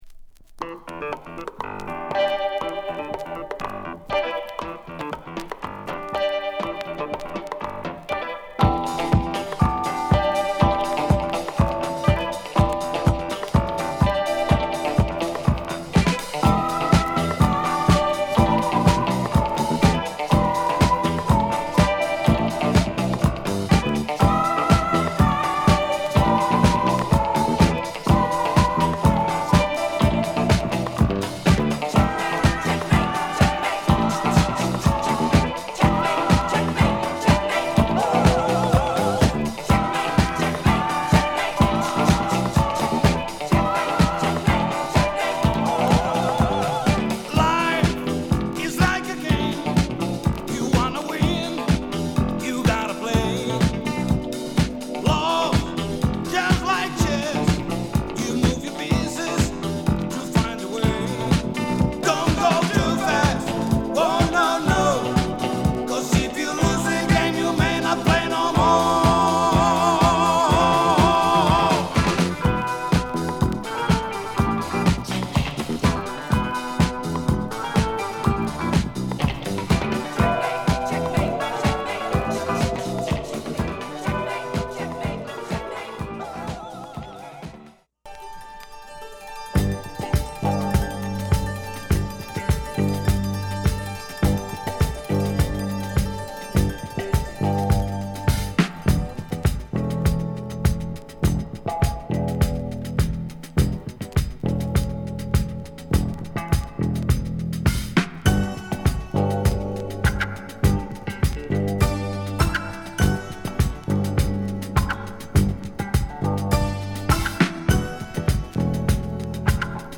＊擦れ多いためセールです。